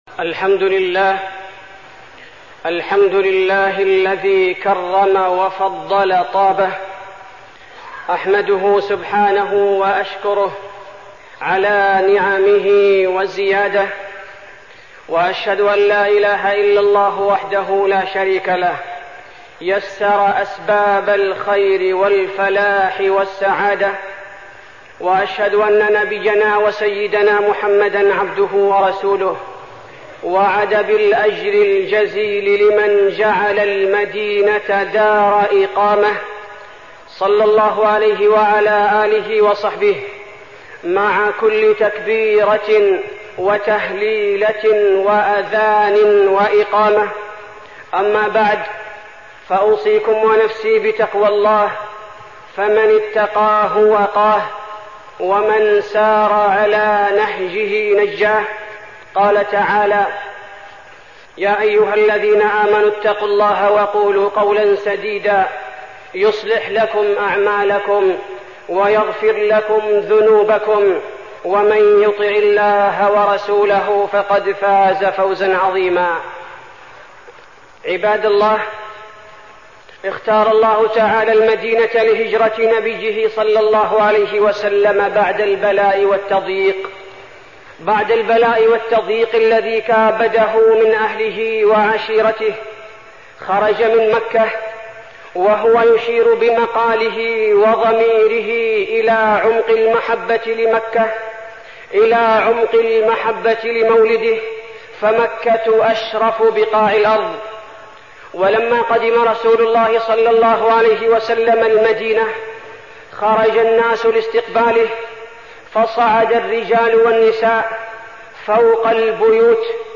تاريخ النشر ٤ ربيع الأول ١٤١٧ هـ المكان: المسجد النبوي الشيخ: فضيلة الشيخ عبدالباري الثبيتي فضيلة الشيخ عبدالباري الثبيتي فضل المدينة